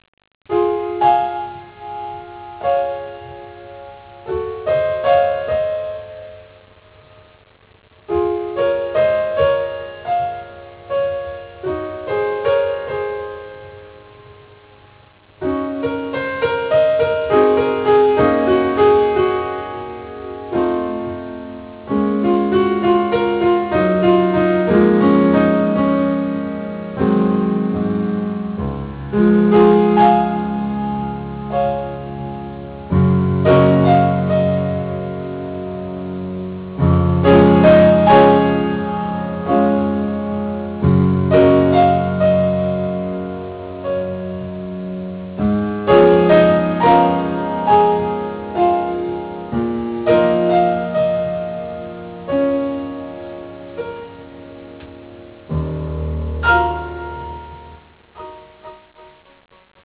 訪問演奏のプログラム例2